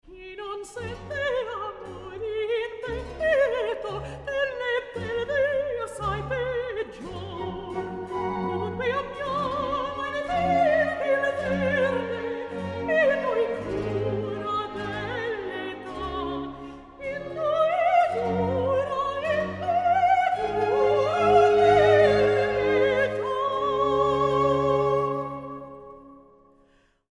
Vocal treasures of the 18th & 19th centuries
Soprano